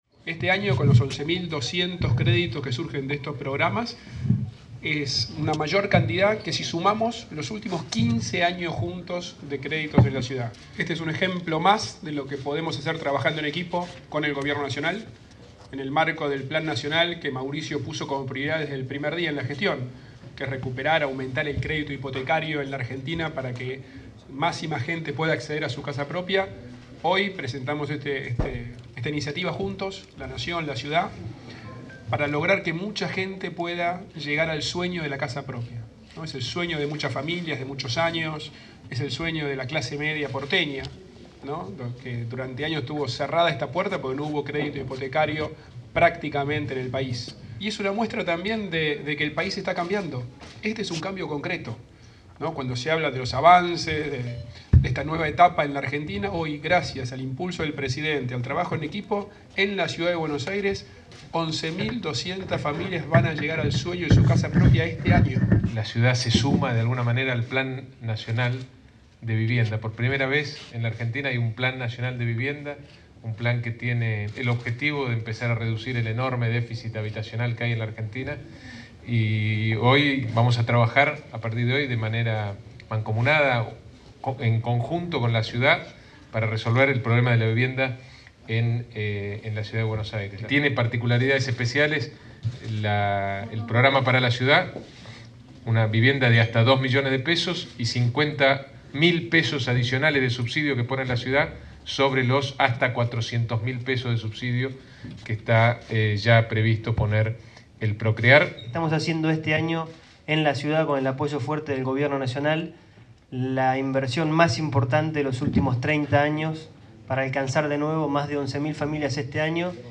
Del acto, que se realizó en la sede del club Ferro Carril Oeste, participaron además el subsecretario de Desarrollo Urbano y Vivienda de la Nación y presidente de ProCreAr, Iván Kerr; el vicejefe de Gobierno porteño, Diego Santilli; el titular del IVC, Juan Maquieyra; y el presidente del Banco Ciudad, Javier Ortiz Batalla.